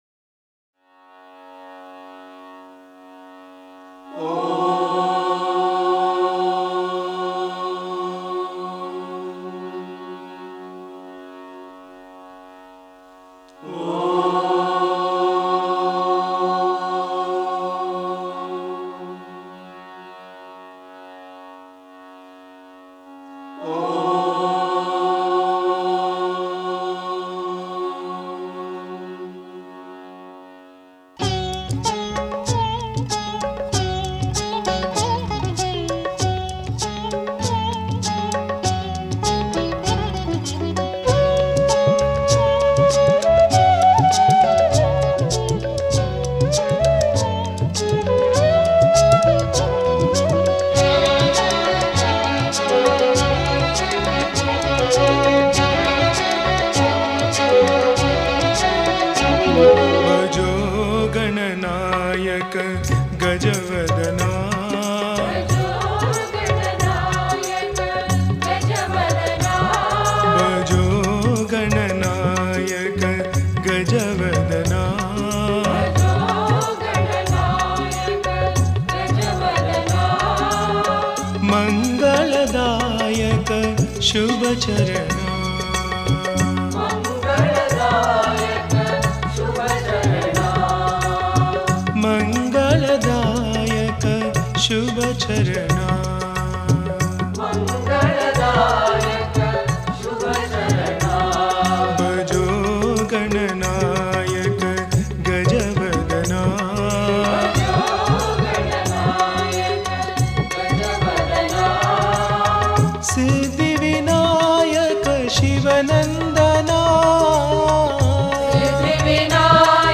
Author adminPosted on Categories Ganesh Bhajans